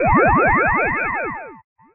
File:LM Early Ghost Disappear Sound.oga
Unused sound clip from Luigi's Mansion
LM_Early_Ghost_Disappear_Sound.oga.mp3